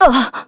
Miruru_L-Vox_Damage_kr_01.wav